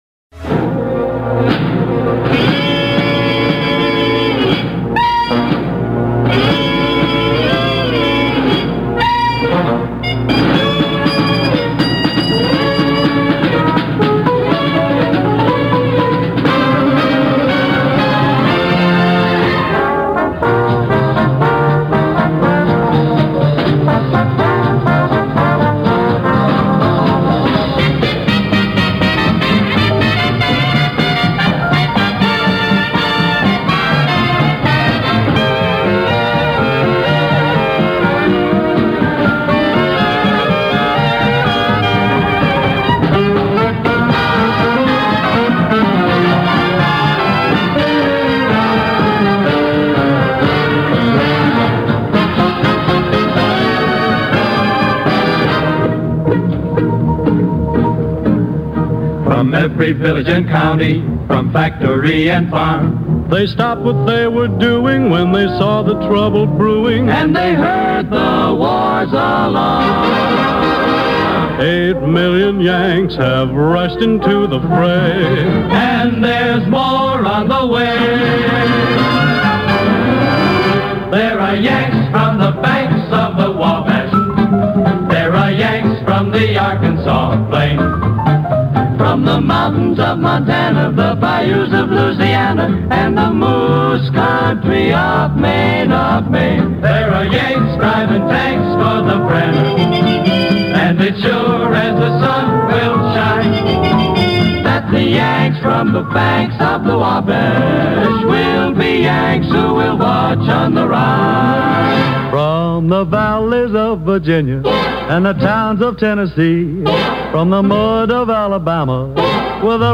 Концерт по заявкам 797 1 ID 139832 Любимые мелодии американских солдат во время войны.